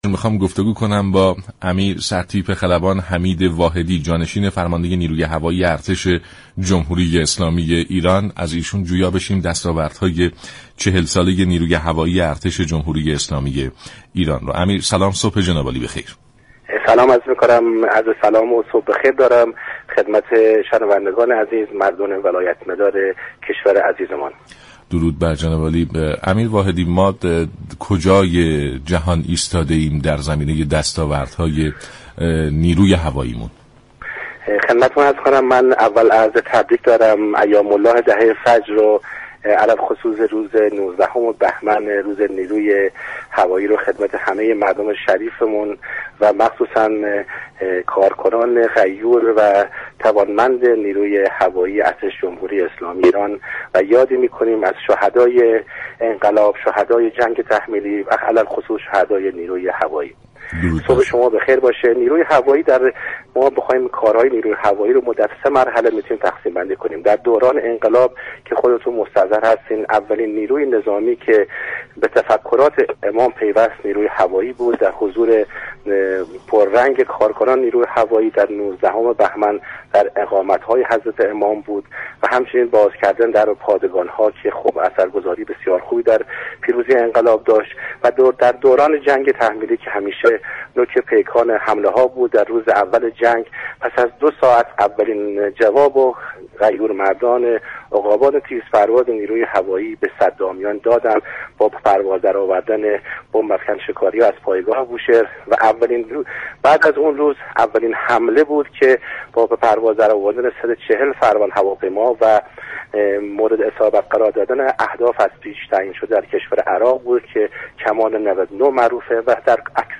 امیر سرتیپ خلبان حمید واحدی جانشین فرمانده نیروی هوایی ارتش جمهوری اسلامی ایران در برنامه صبح انقلاب رادیو ایران گفت : نیروهای ما در ساخت هواپیماهایی از جمله صاعقه و كوثر نقش مهمی داشتند